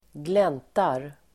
Uttal: [²gl'en:tar]